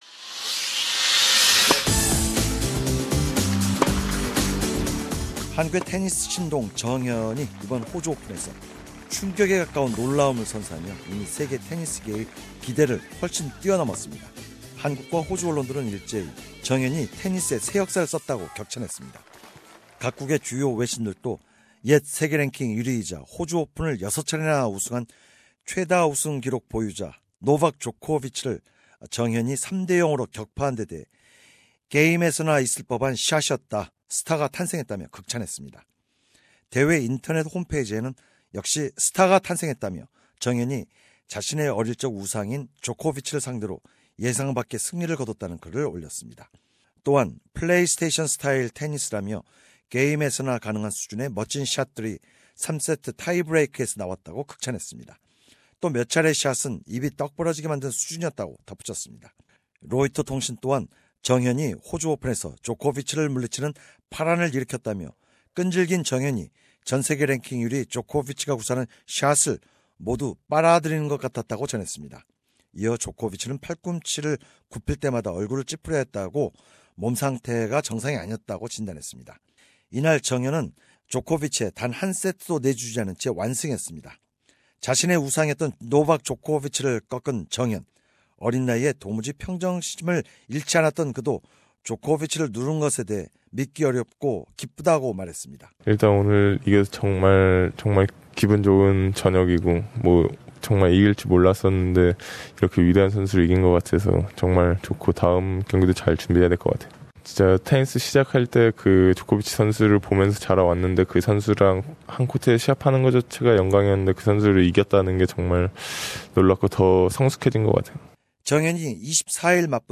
South Korean tennis prodigy Chung Hyeon who has rewritten tennis history after Zverev-Djokovic upsets said "I can't believe this" in an exclusive interview with SBS Radio.